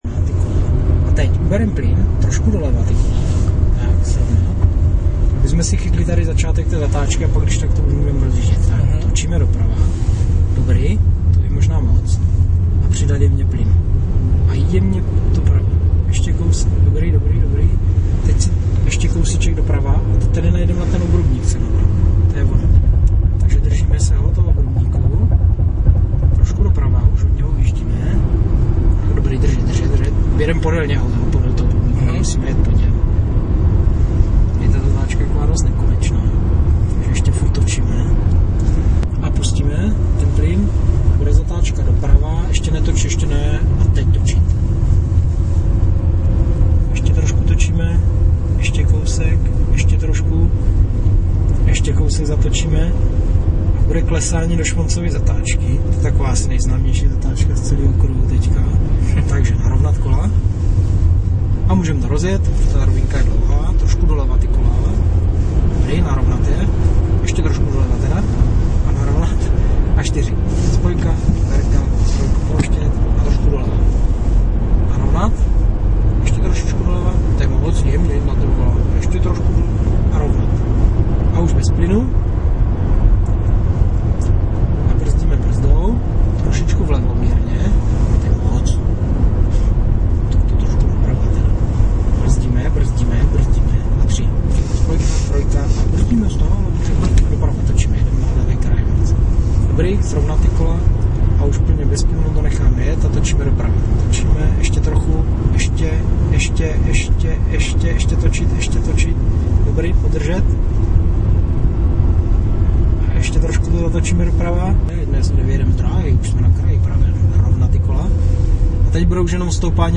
ukázka popisu trasy).